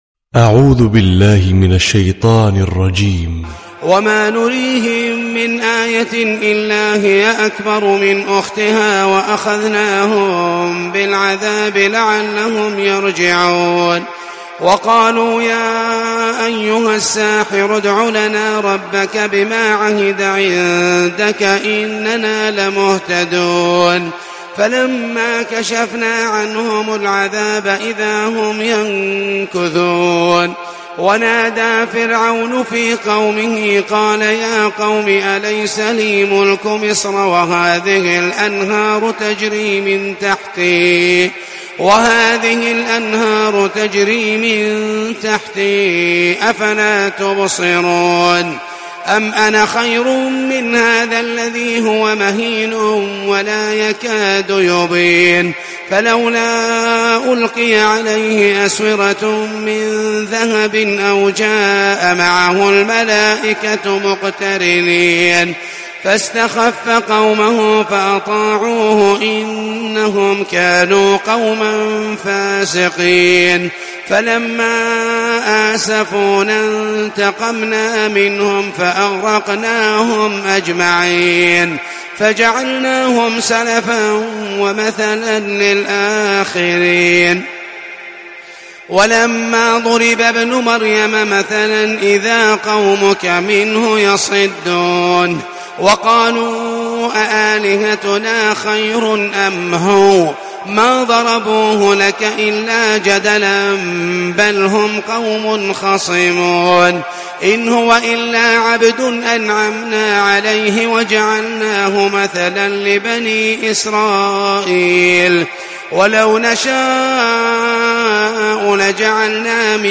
🕋🌻●تلاوة صباحية●🌻🕋
🎙 القارئ : محمد المحيسني